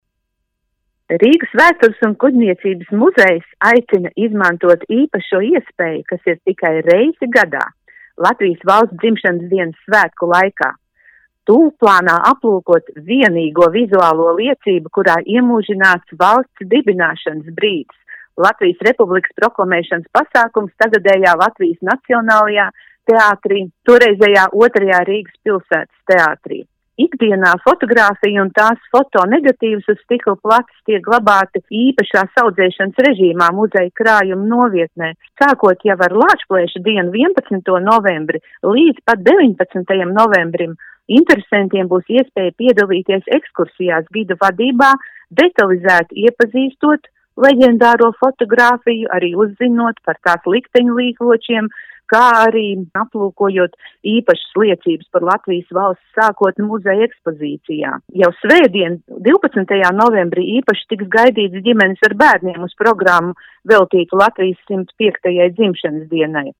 RADIO SKONTO Ziņās par Rīgas vēstures un kuģniecības muzejā apskatāmo unikālo fotogrāfiju